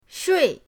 shui4.mp3